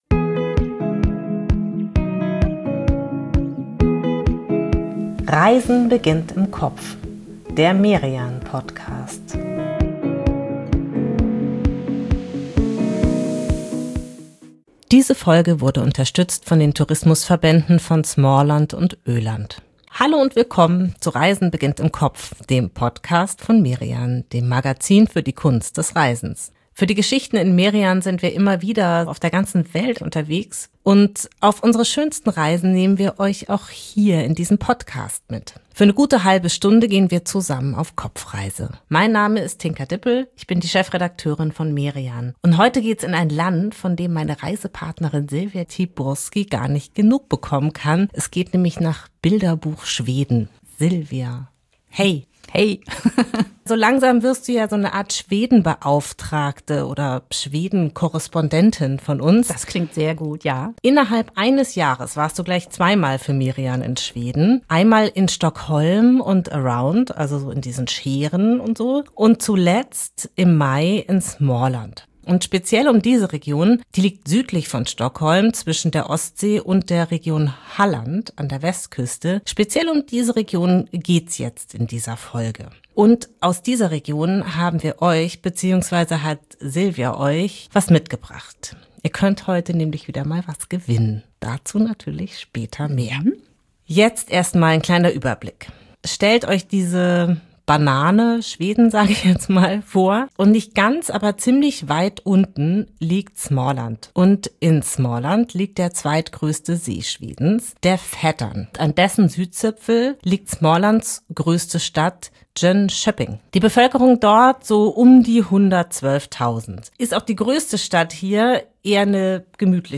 Aber es wird noch besser: Zwei Spitzenköche singen ein schwedisches Trinklied, und: Es gibt etwas zu gewinnen.